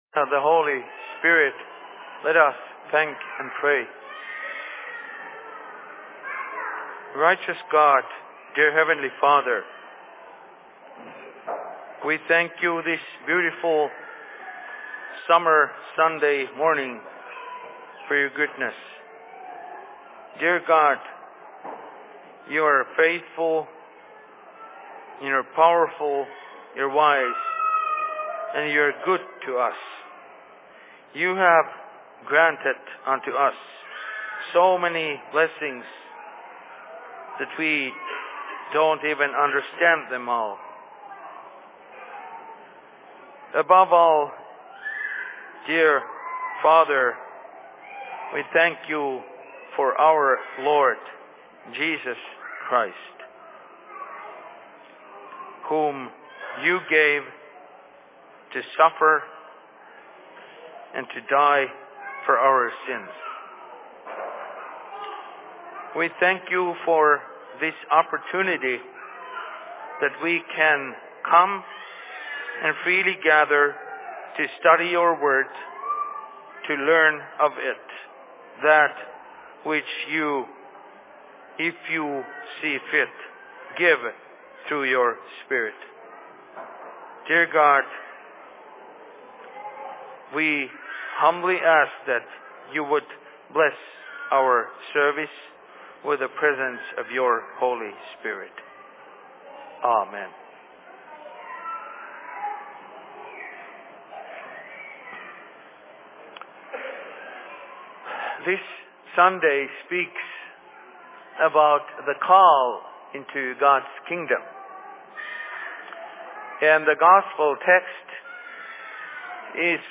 Sermon in Rockford 29.06.2014
Location: LLC Rockford